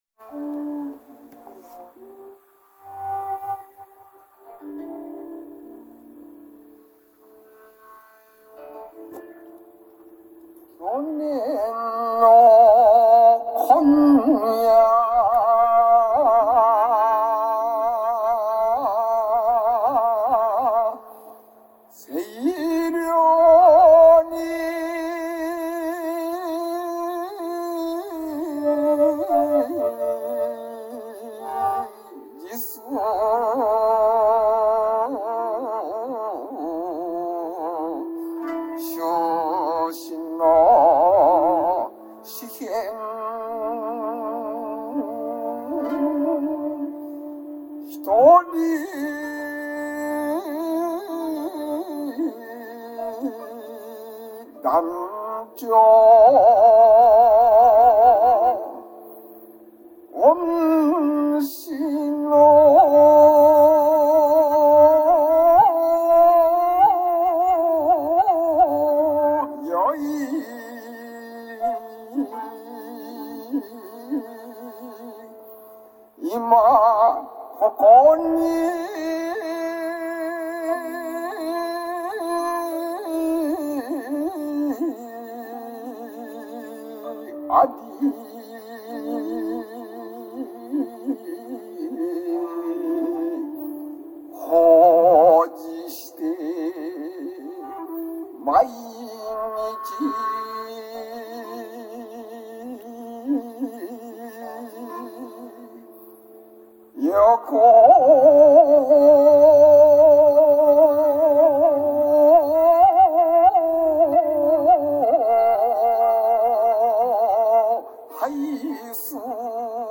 漢詩や和歌に節（ふし）をつけて歌うことを「詩吟」（しぎん）